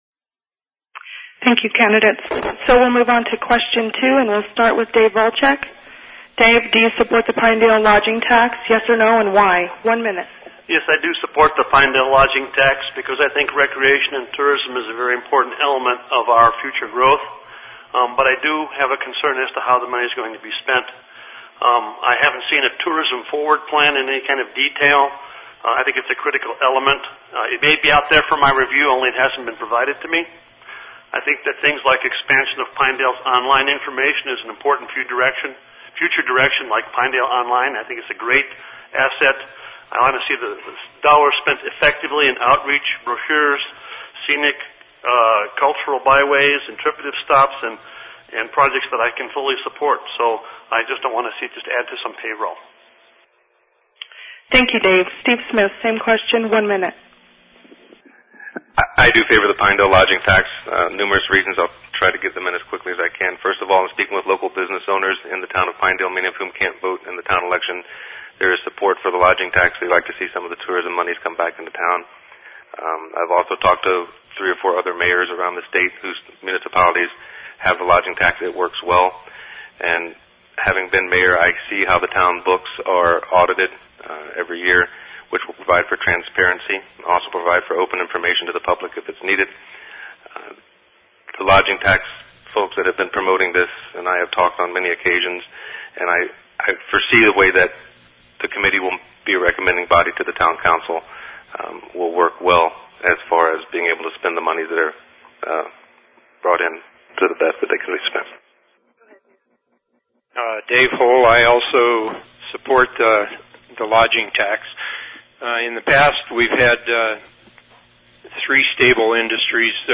Below are the audio files from the Wednesday, April 28, 2010 Candidate Forum in the Lovatt Room of the Sublette County Library in Pinedale. Candidates for Town of Pinedale Mayor (M) and two open Town Council (TC) positions participated.